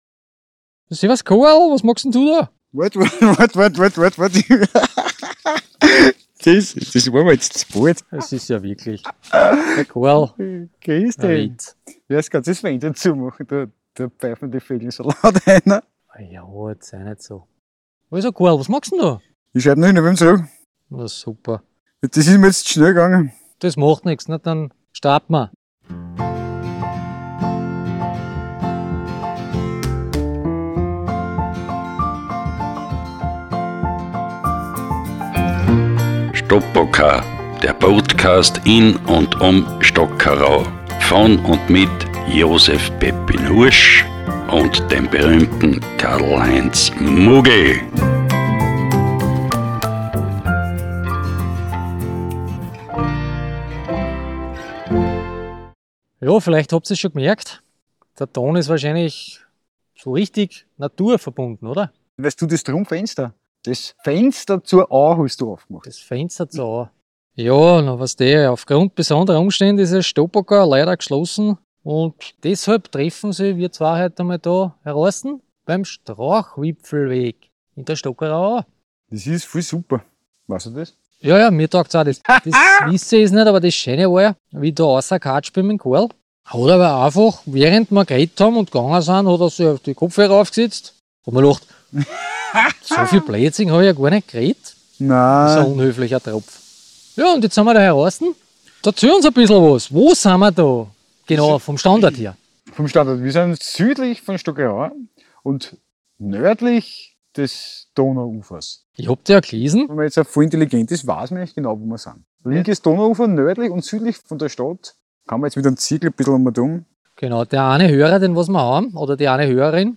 diesmal in der Stockerauer Au, genauer gesagt auf dem im Frühling eröffneten Strauchwipfelweg. Und ein Jubiläum gibt es auch zu feiern: 10 Jahre Stopoca.